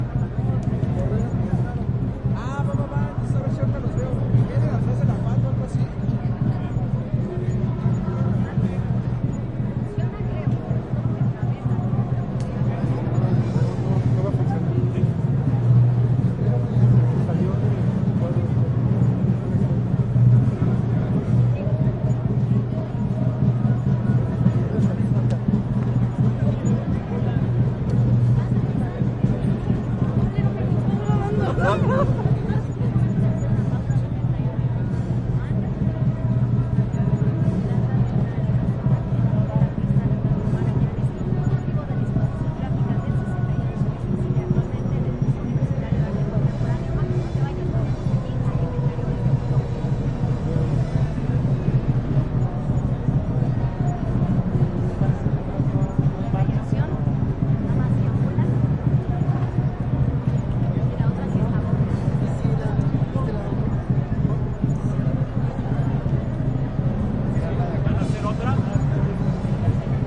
标签： 人群 游行 示威 聚众
声道立体声